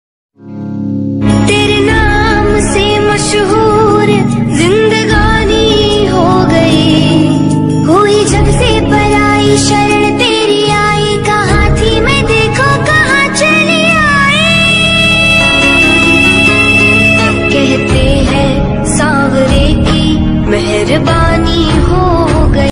bhakti ringtone